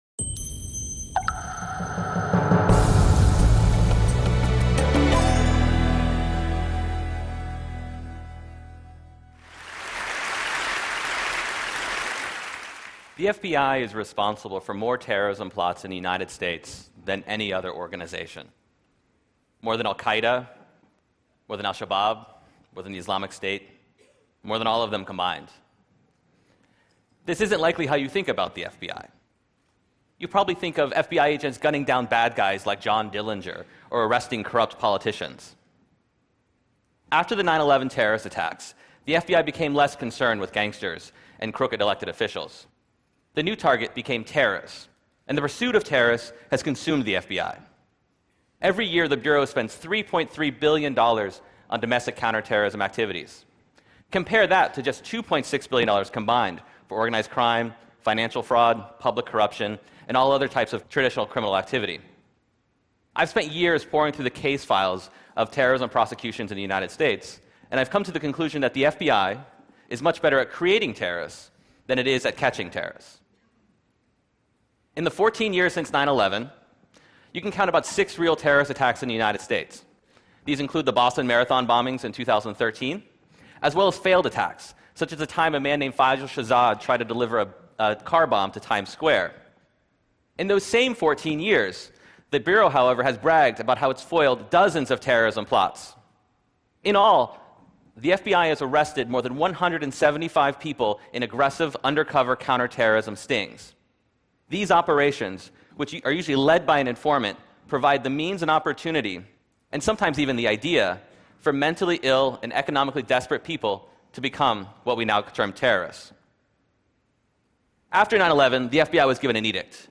TEDTalks is a daily video podcast of the best talks and performances from the TED Conference, where the world’s leading thinkers and doers give the talk of their lives in 18 minutes (or less).